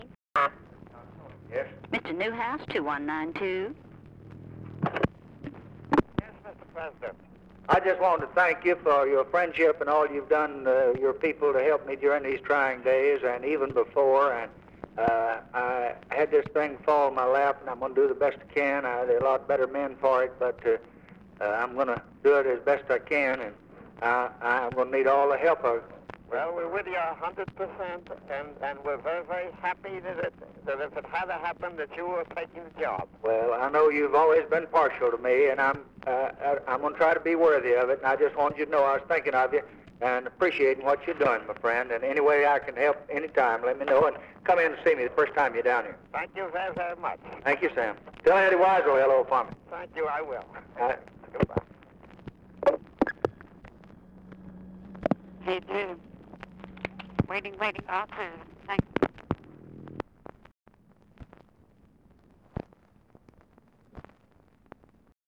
Conversation with SAMUEL NEWHOUSE, November 27, 1963
Secret White House Tapes